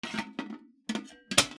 やかんのふた 閉める キッチン
『シュルシュル スチャン』